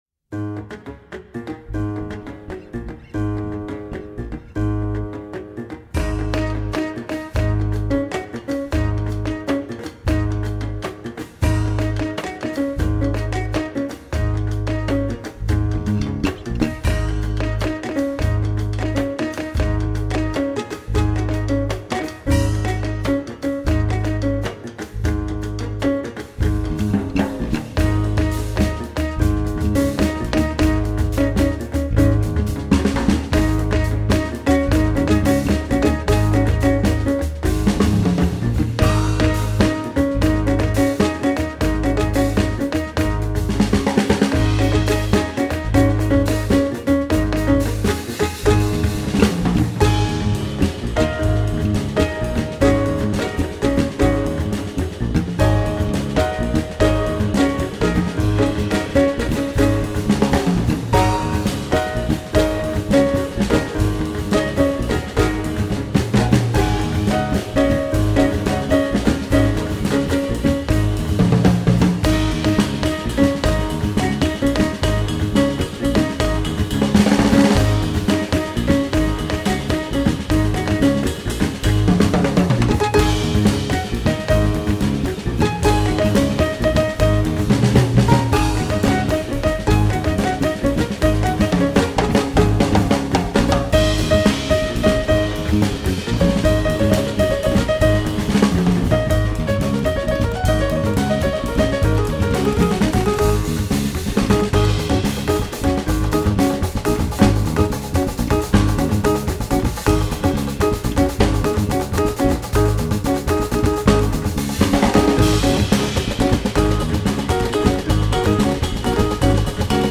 World Jazz.
plenty highlife feel